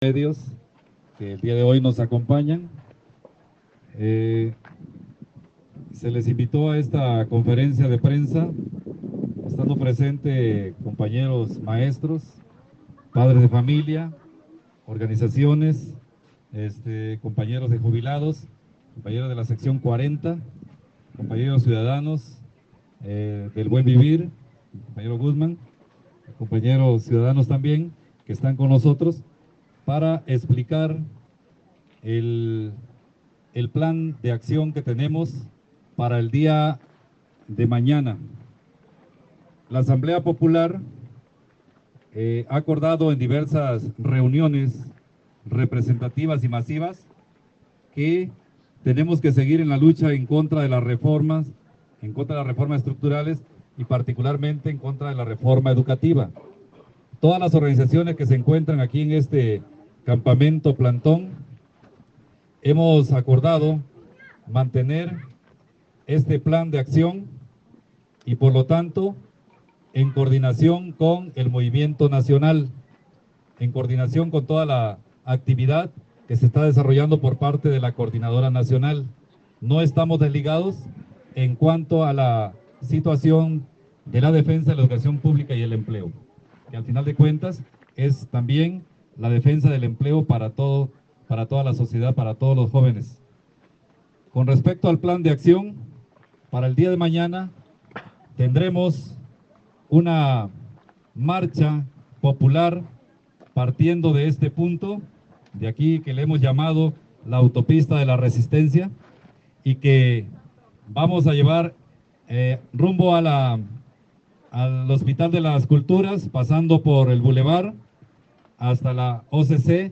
Conferencia de prensa del magisterio y movimiento popular